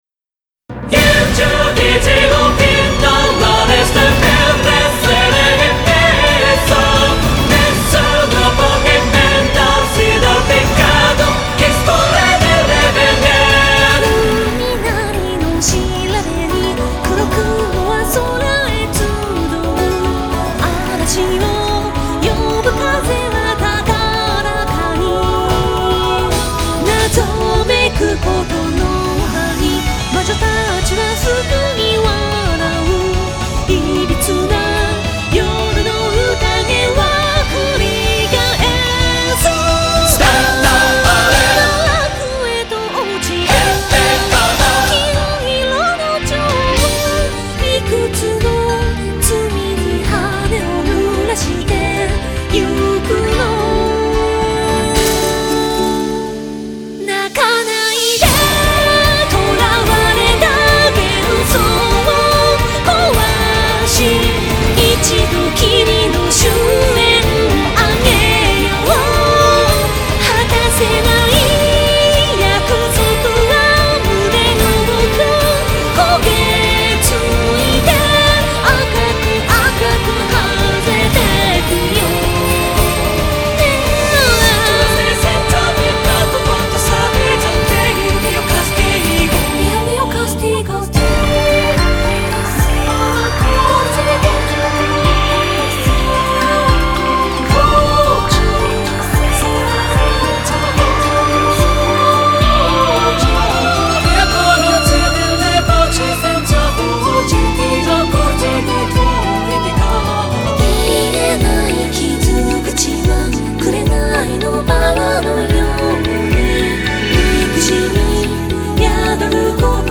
Anime opening